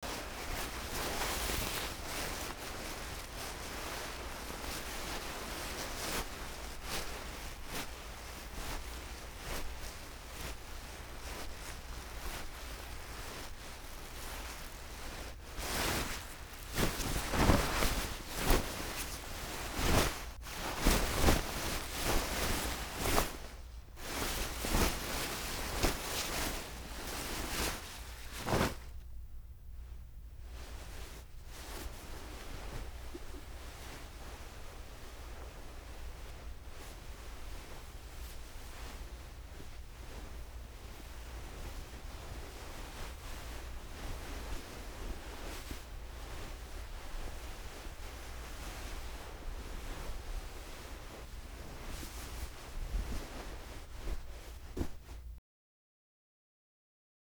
household
Cloth Heavy Rustle and Crunch